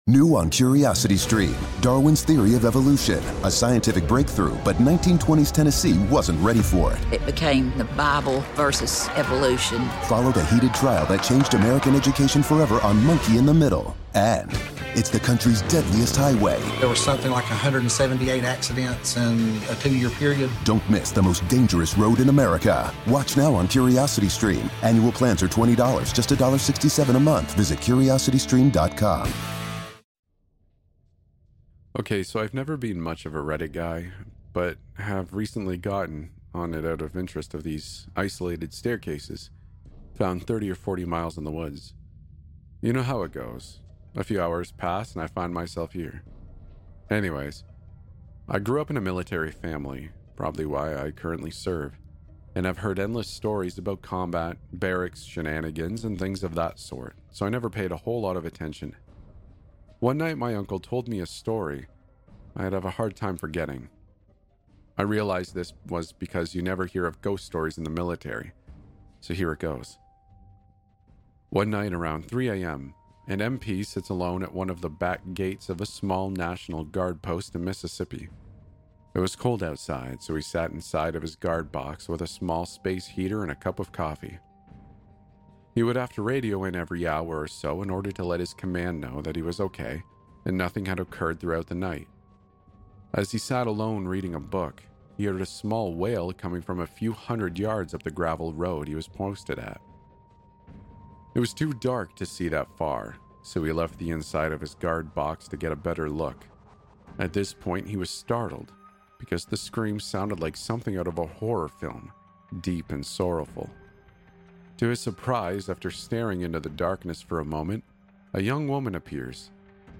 I hope you enjoy three pretty creepy nosleep stories I found on Reddit. I had a good time reading them, now I hope you have a better time listening.